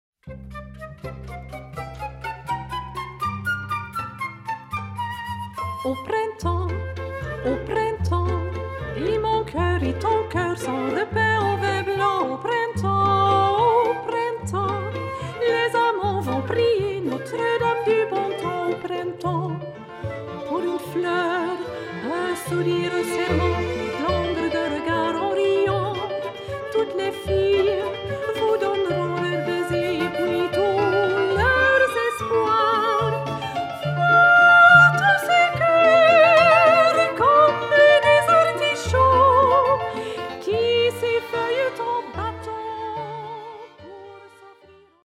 soprano
flûte
hobo
violon
viola
cello
piano